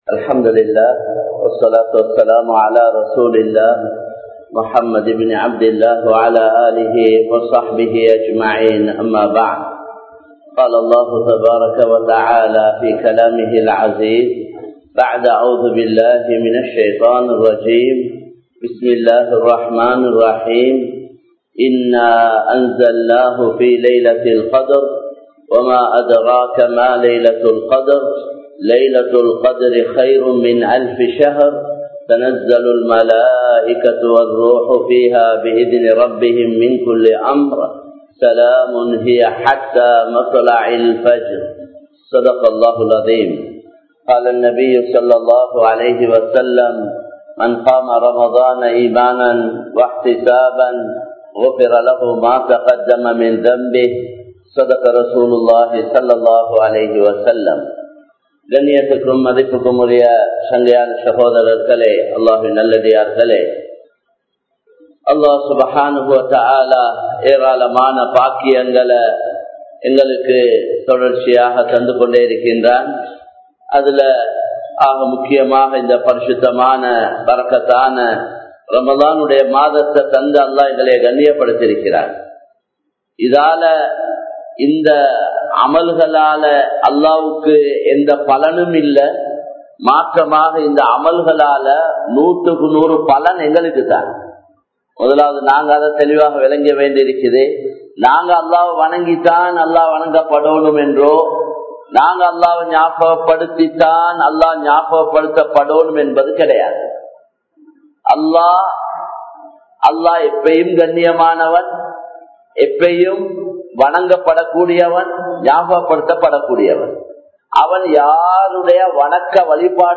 லைலத்துல் கத்ர் | Audio Bayans | All Ceylon Muslim Youth Community | Addalaichenai